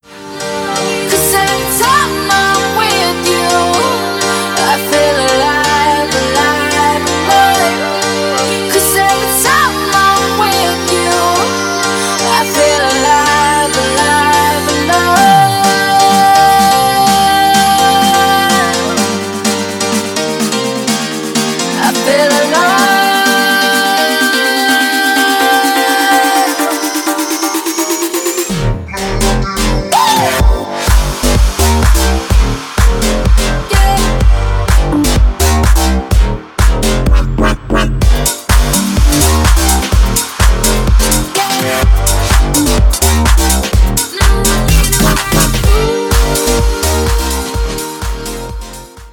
• Качество: 320, Stereo
женский вокал
dance
future house
club